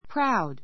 proud 中 práud プ ラ ウ ド 形容詞 ❶ （いい意味で） 誇 ほこ りを持っている, プライド[自尊心]がある a proud girl a proud girl 誇りを持っている女の子 We are proud of our mother.